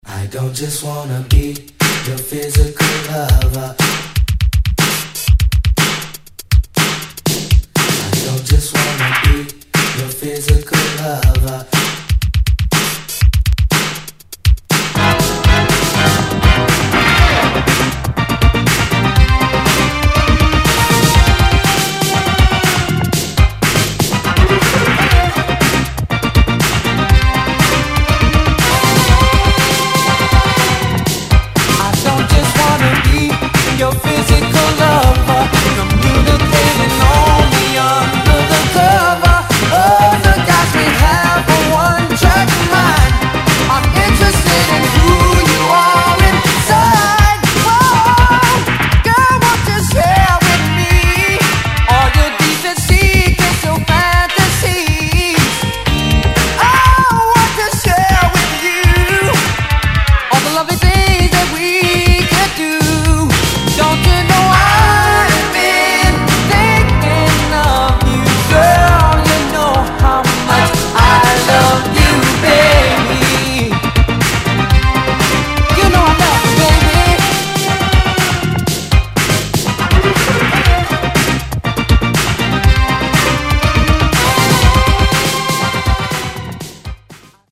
Detroit出身の兄弟グループによるUrban Electro Disco~Funk!!